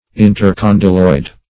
Search Result for " intercondyloid" : The Collaborative International Dictionary of English v.0.48: Intercondylar \In`ter*con"dy*lar\, Intercondyloid \In`ter*con"dy*loid\, a. (Anat.) Between condyles; as, the intercondylar fossa or notch of the femur.